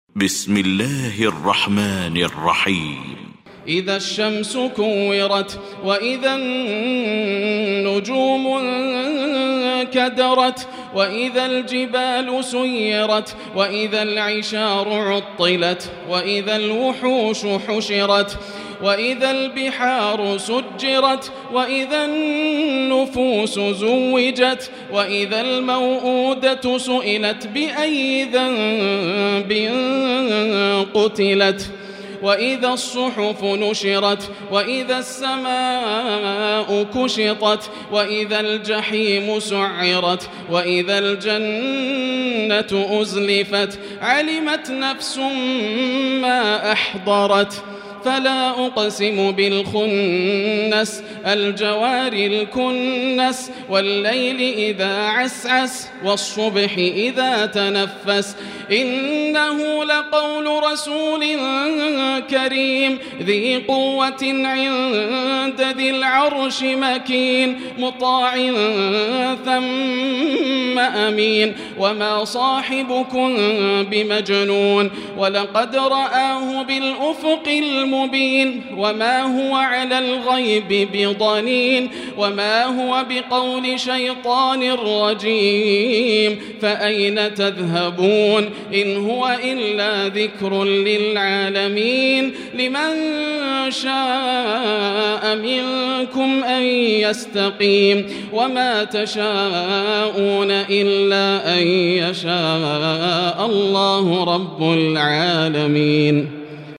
المكان: المسجد الحرام الشيخ: فضيلة الشيخ ياسر الدوسري فضيلة الشيخ ياسر الدوسري التكوير